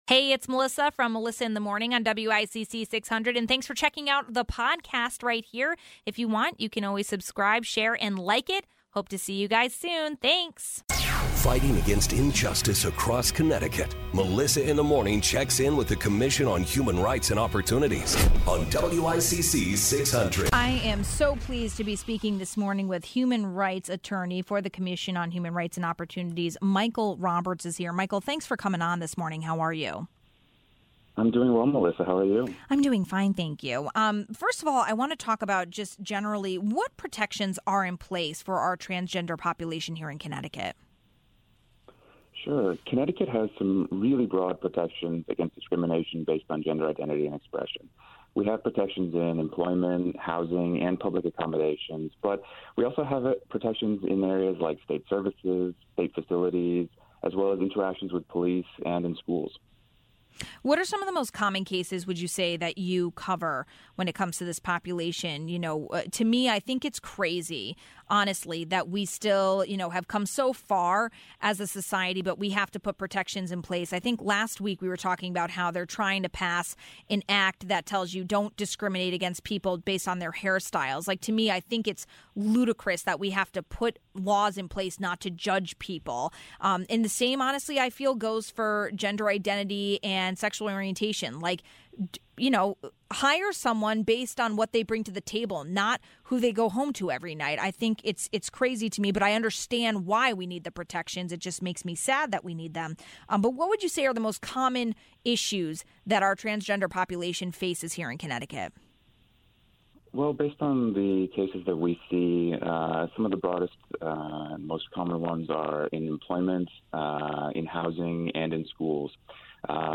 1. A human rights attorney talks about transgender and gender neutral protections in CT. ((00:10))
((00:10)) 2. Sen. Duff talks about marijuana legalization and religious exemptions for child vaccinations. ((10:56)) 3. Sen. Hwang talks about the opposing side of legalized marijuana and frustrations over the public health option.